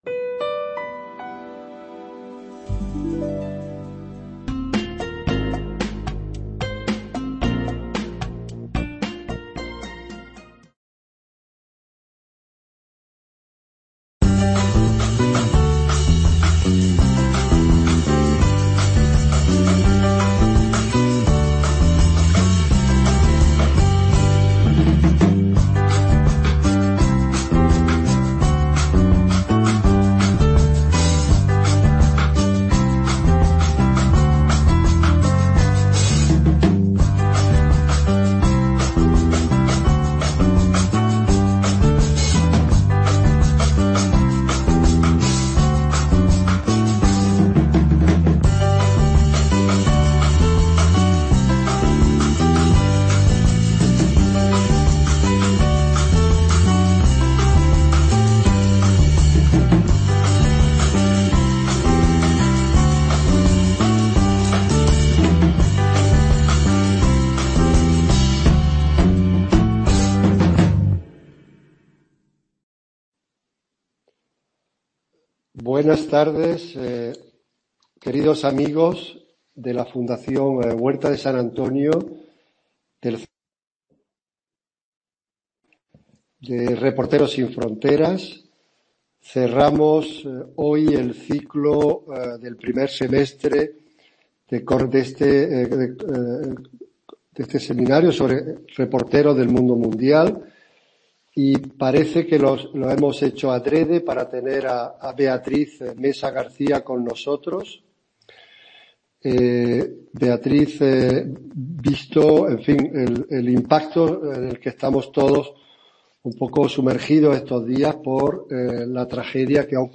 Diálogo